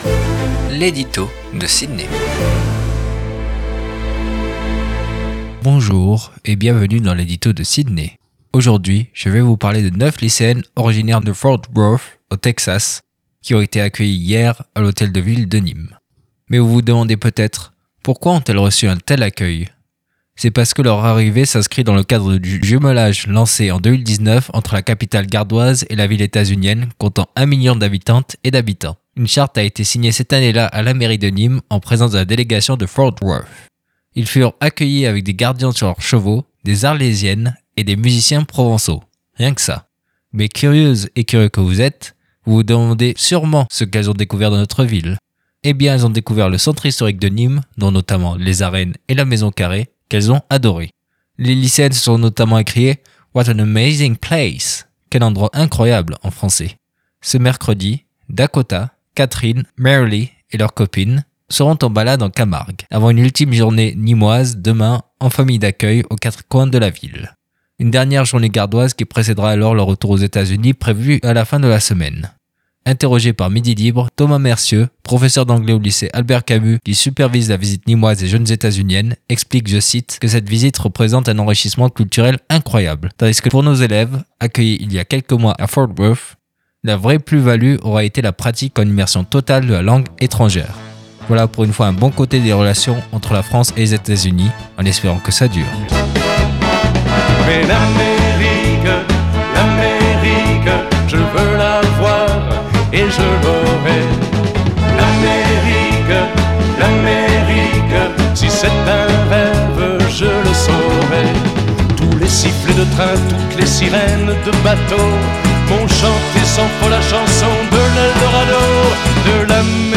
DES LYCÉENNES TEXANES DÉCOUVRENT NÎMES - L'EDITO